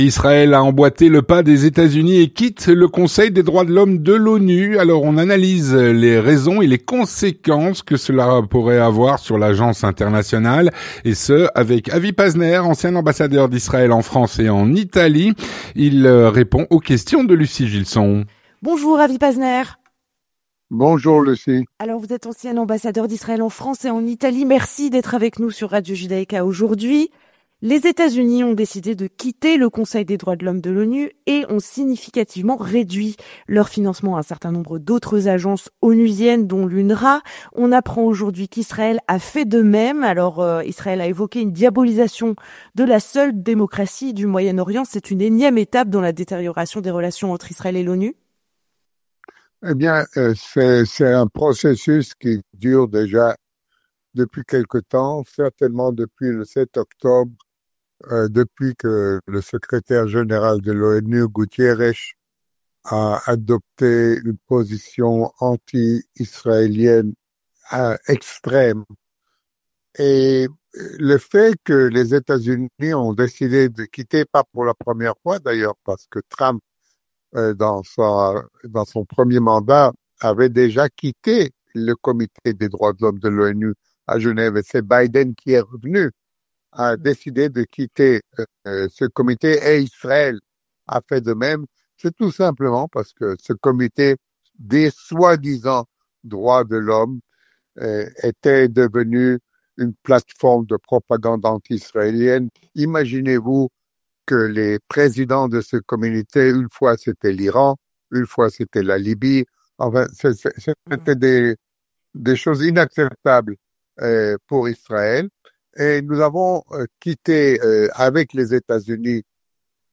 L'entretien du 18H - Israël emboîte le pas des États-Unis et quitte le Conseil des droits de l'homme de l'ONU. Avec Avi Pazner (07/02/2025)